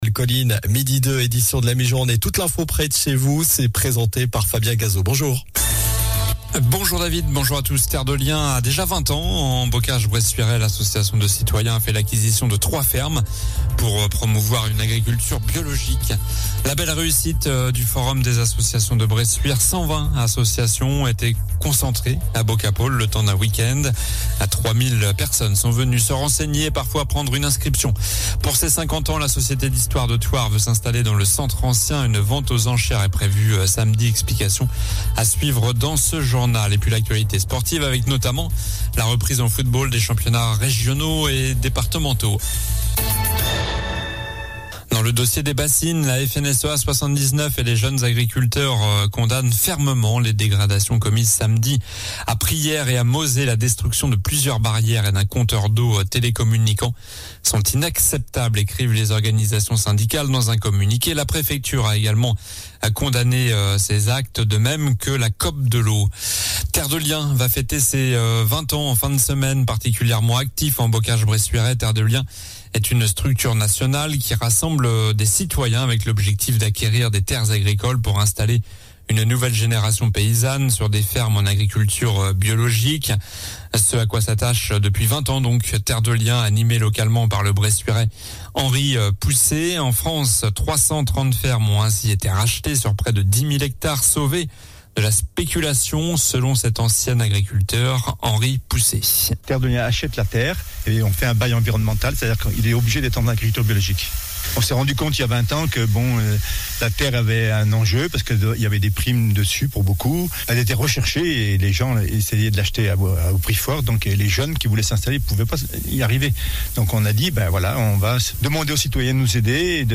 Journal du lundi 11 septembre (midi)